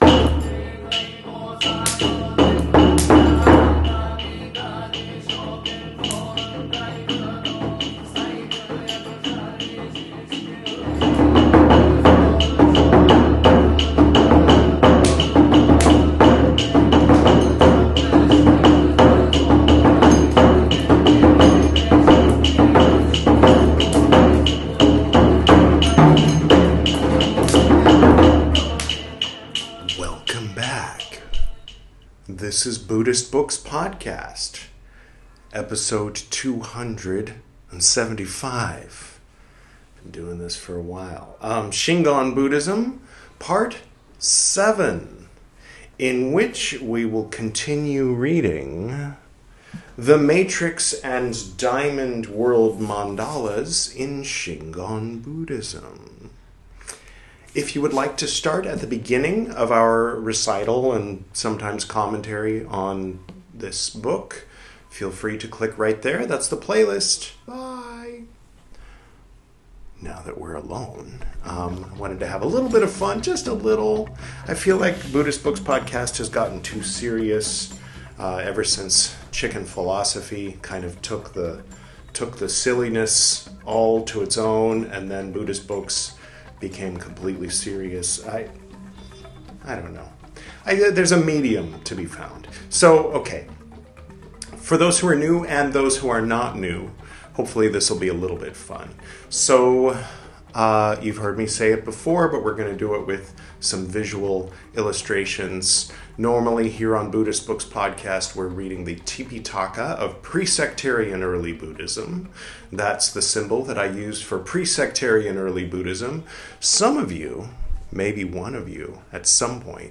This is Part 7 of my recital of the 'The Matrix and Diamond World Mandalas in Shingon Buddhism' by Adrian Snodgrass. Shingon Buddhism is, put simply, the Japanese version of Vajrayana Buddhism.